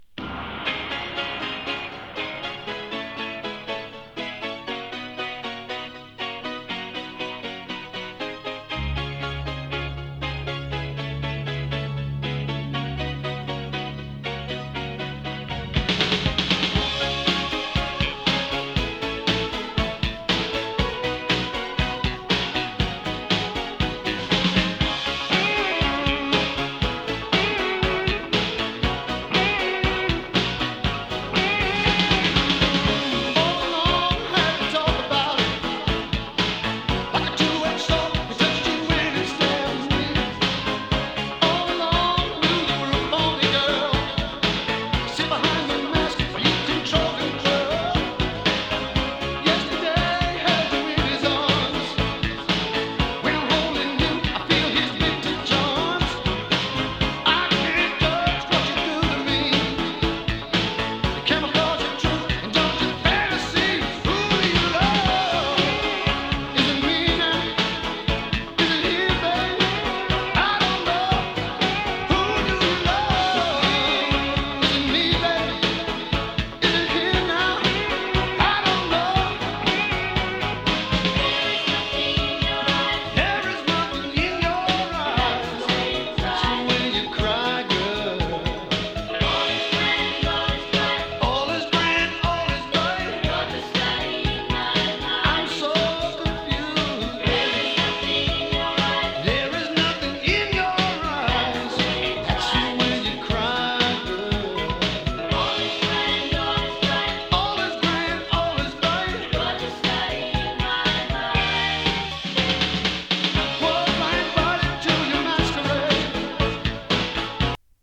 スローハンドの異名を持つ名ギタリスト/シンガー。